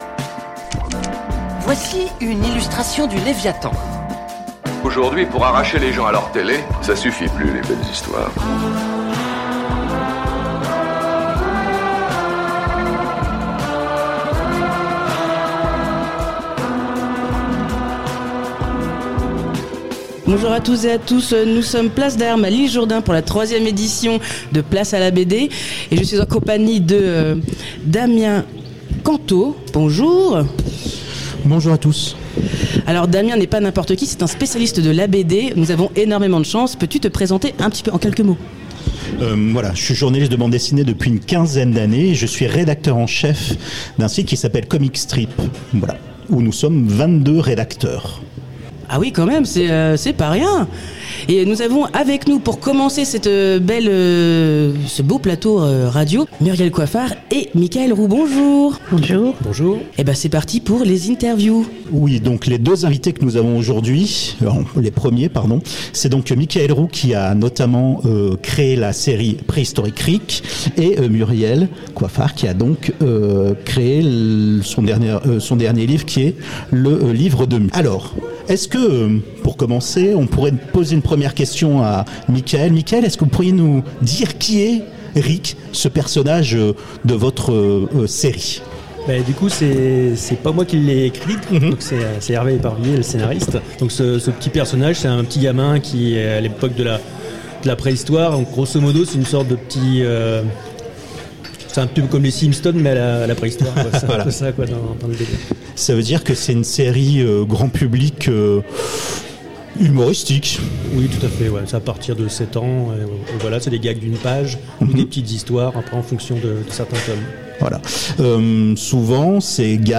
Salon Place à la BD à L'Isle Jourdain